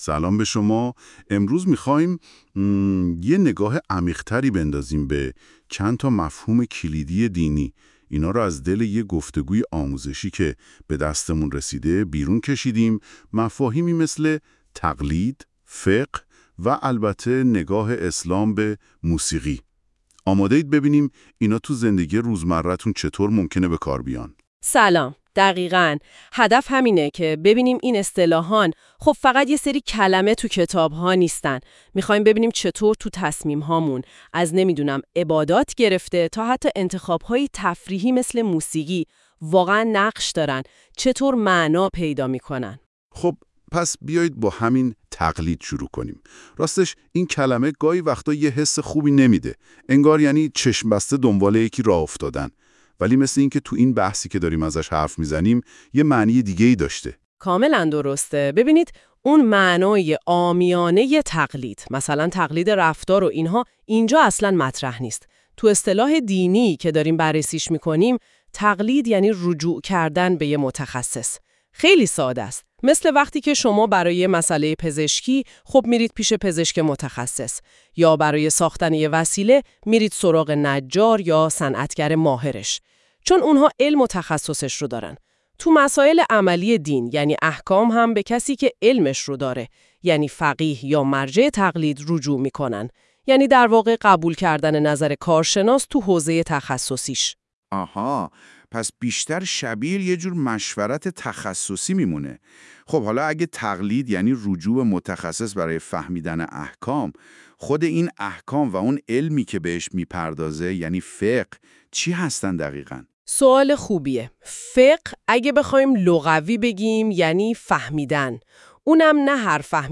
[با توجه به اینکه پادکست توسط هوش مصنوعی تولید می شود، ممکن است برخی از کلمات اشتباه تلفظ شود؛